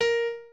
pianoadrib1_3.ogg